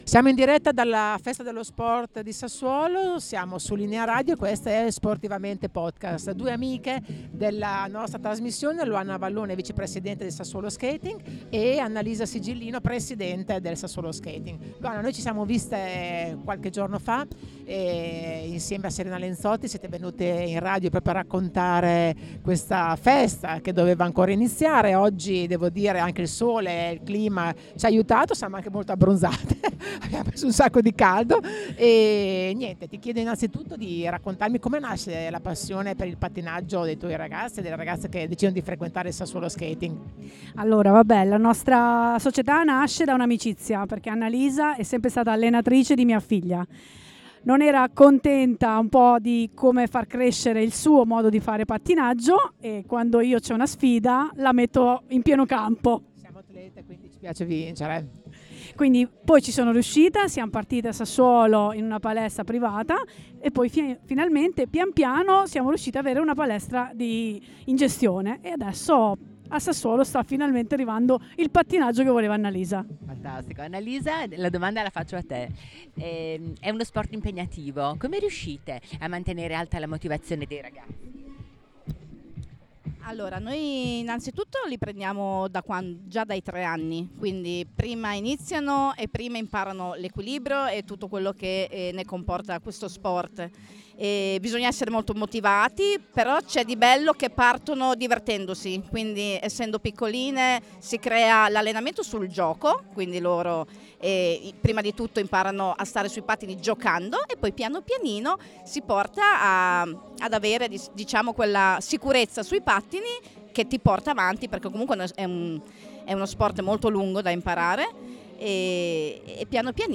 Festa dello sport 2025
Intervista